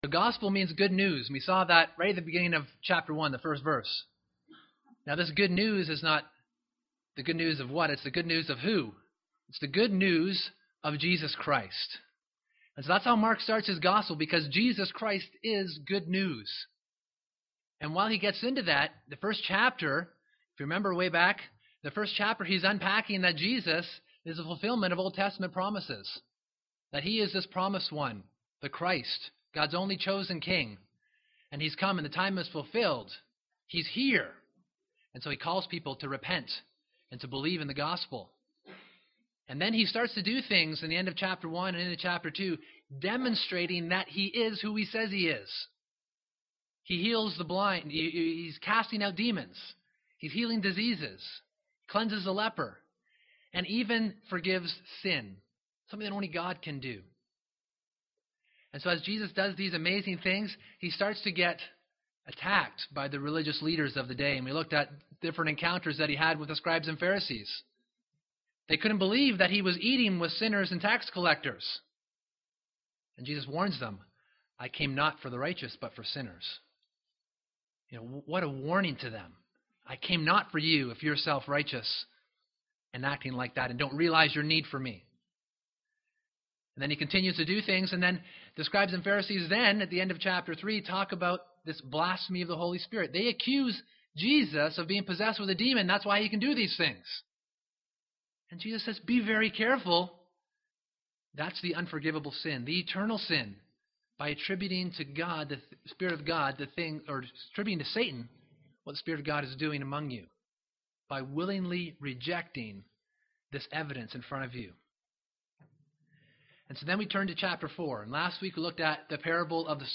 This book was given to make and encourage disciples of Jesus, and this sermon series seeks to do the same by proclaiming Mark's message for today's generation.